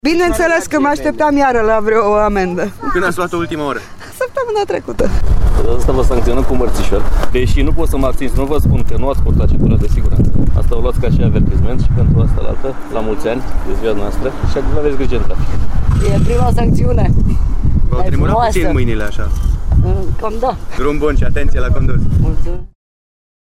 Doamnele şi domnişoarele depistate la volan au fost “sancţionate” cu un mărţişor, chiar dacă au încălcat, pe ici pe colo, legislaţia rutieră. Cele mai multe şoferiţe au fost surprinse de gestul poliţiştilor: